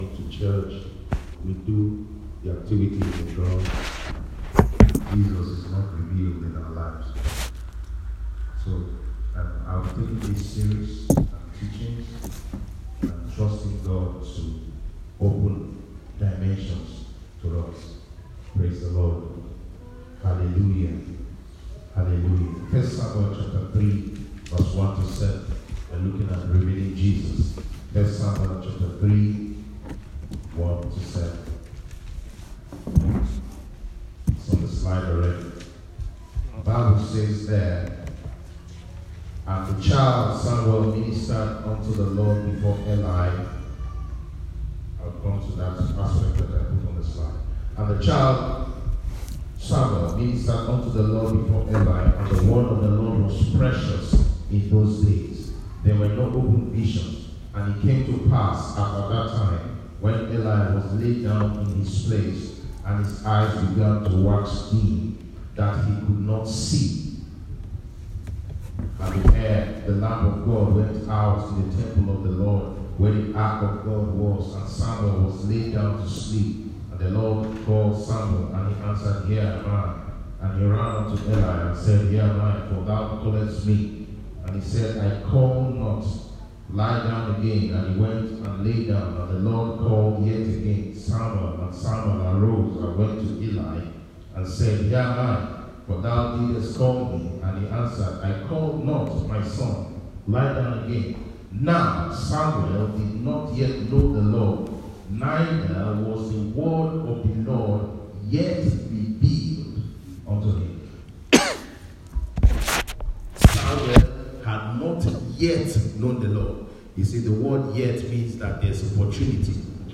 - RCCG - KingCity Perth Western Australia